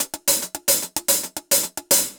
Index of /musicradar/ultimate-hihat-samples/110bpm
UHH_AcoustiHatB_110-01.wav